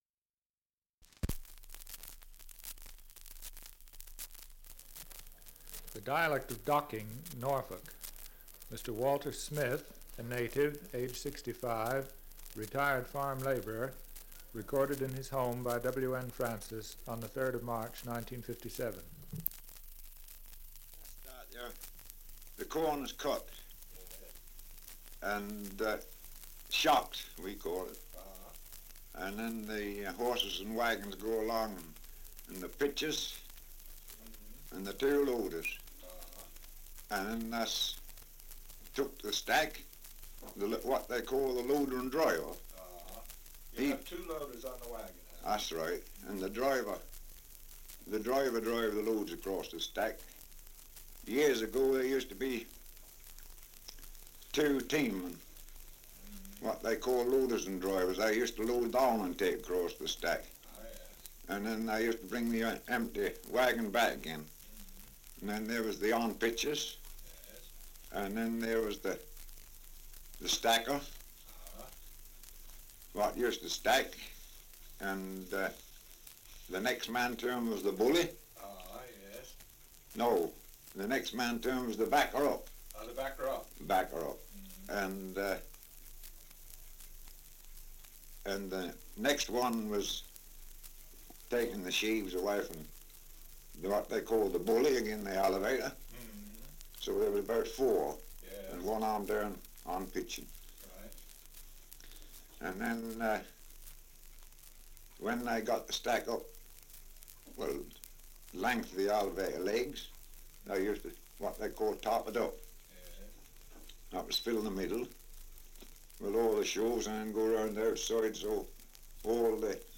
Survey of English Dialects recording in Docking, Norfolk
78 r.p.m., cellulose nitrate on aluminium